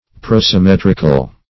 Search Result for " prosimetrical" : The Collaborative International Dictionary of English v.0.48: Prosimetrical \Pros`i*met"ric*al\, a. [Prose + metrical.] Consisting both of prose and verse.